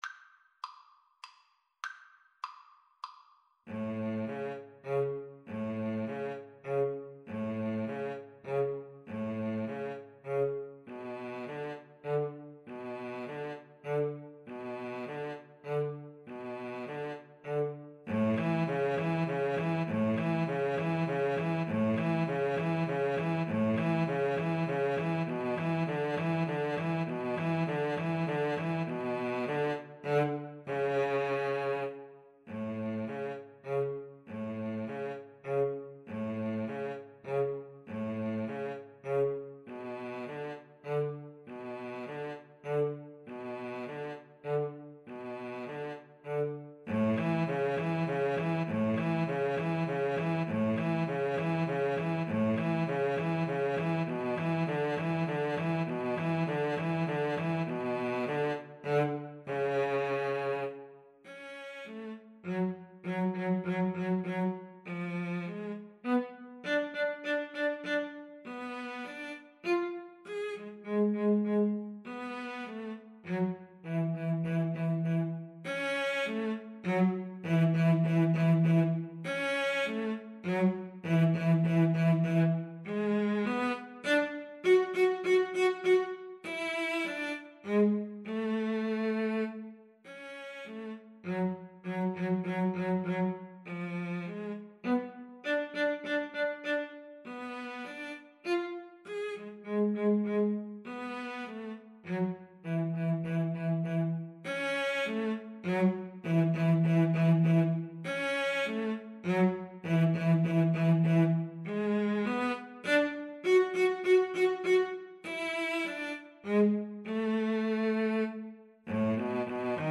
Free Sheet music for Violin-Cello Duet
ViolinCello
3/4 (View more 3/4 Music)
A major (Sounding Pitch) (View more A major Music for Violin-Cello Duet )
Traditional (View more Traditional Violin-Cello Duet Music)